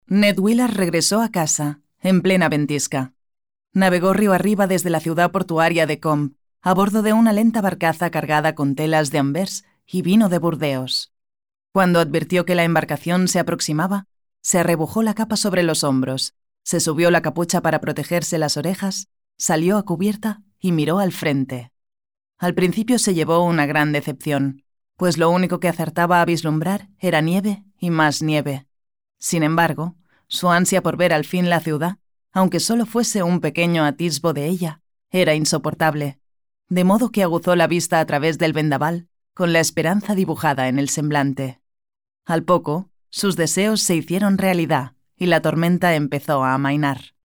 Warm, Soft, Natural, Friendly, Young
Explainer